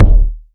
Kicks
KICK.142.NEPT.wav